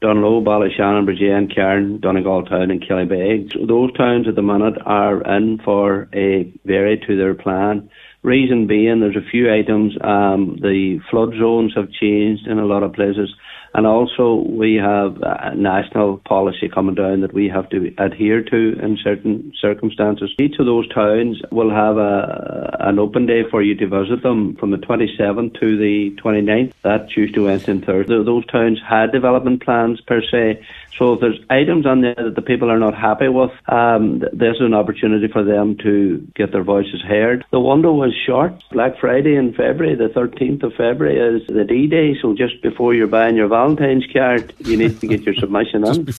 Donegal County Council Cathaoirleach Cllr Paul Canning